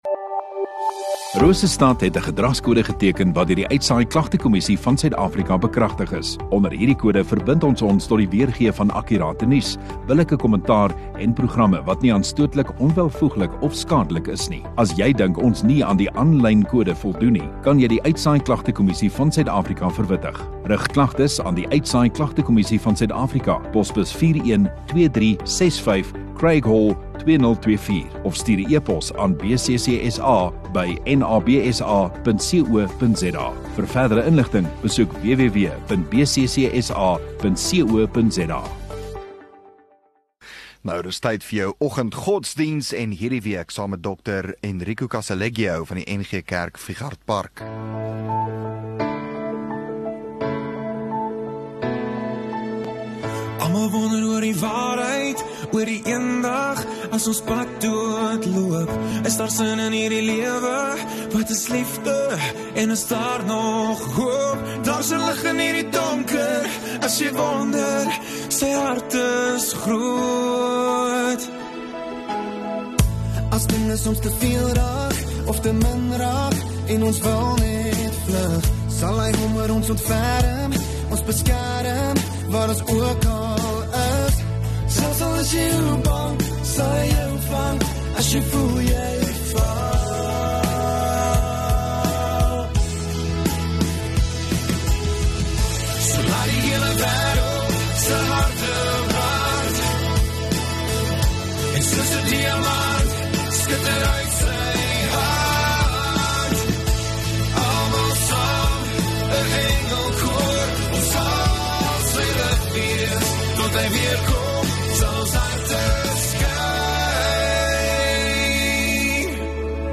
8 Jul Dinsdag Oggenddiens